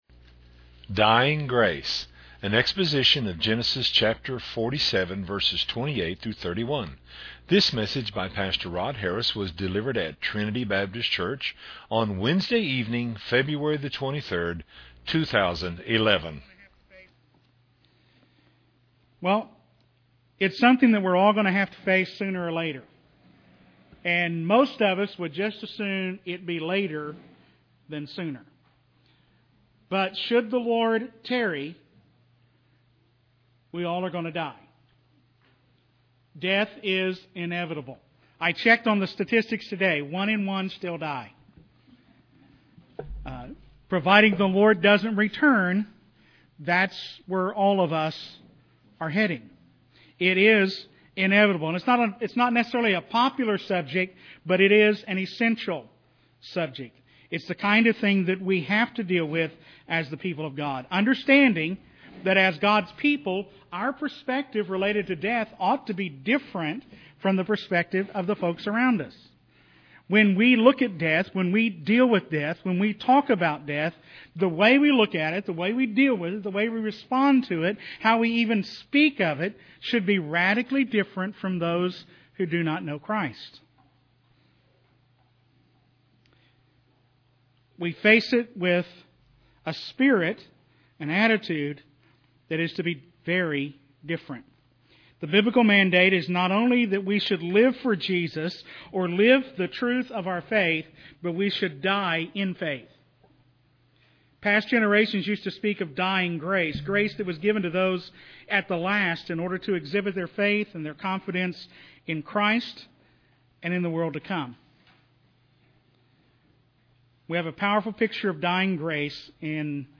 was delivered at Trinity Baptist Church on Wednesday evening, February 23, 2011.